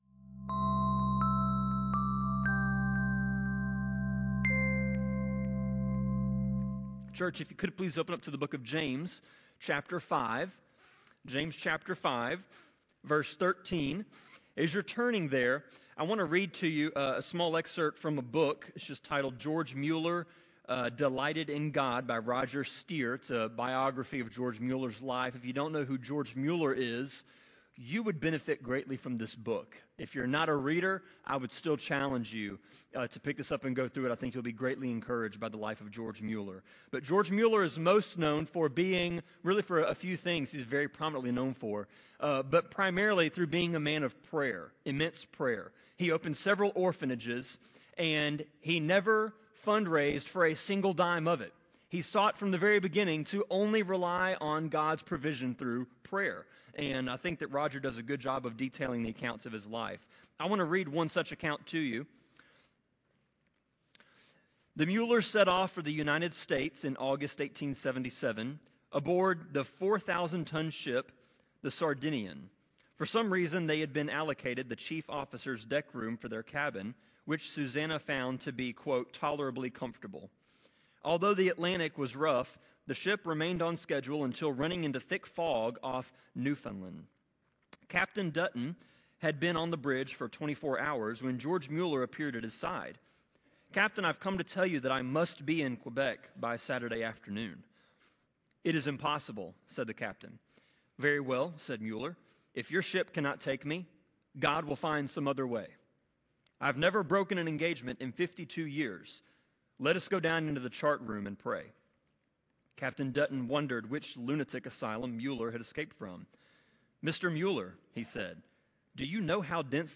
Sermon-25.8.17-CD.mp3